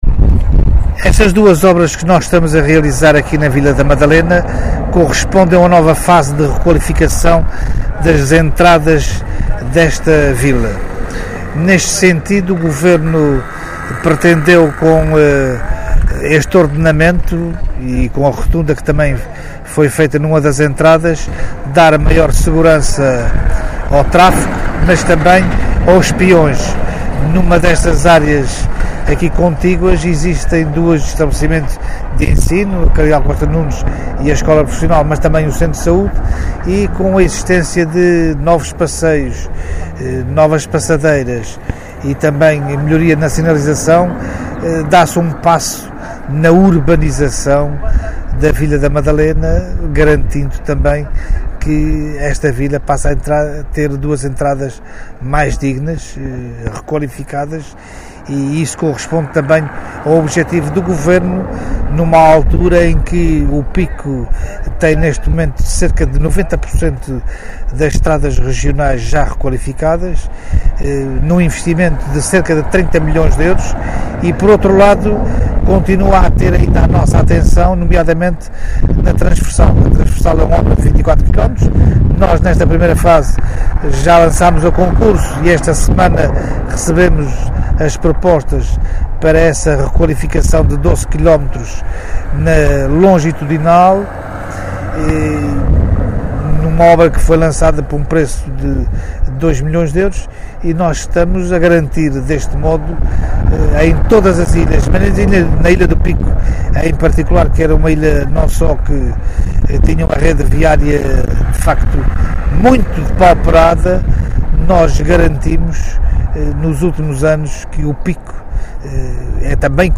O Secretário Regional visitou hoje as obras da empreitada de construção da rotunda do Carmo e da requalificação da Rua D. Jaime Garcia Goulart, E.R. n.º 1-2ª, na Madalena do Pico, no âmbito da visita estatutária, e sublinhou a importância destas obras, que irão disciplinar o tráfego e, ao mesmo tempo, reforçar a segurança rodoviária.